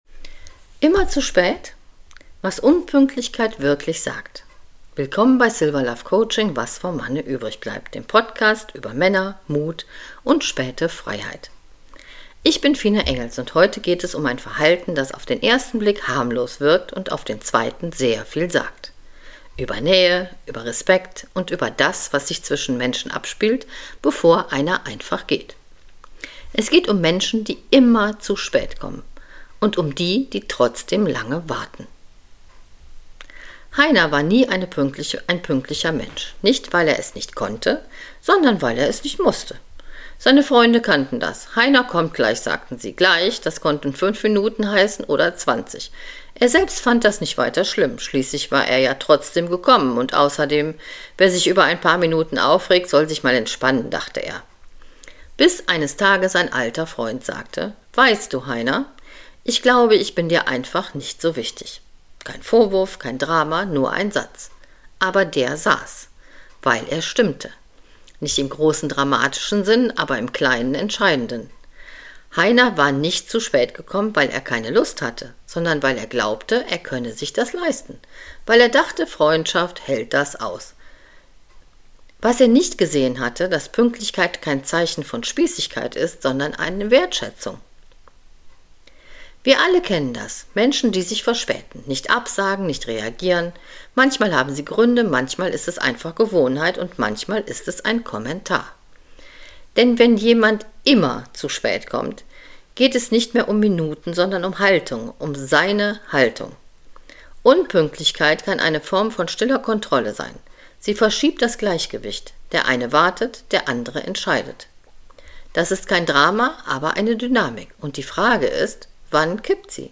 ruhigen Coaching-Teil am Ende: ehrlich, direkt, nicht belehrend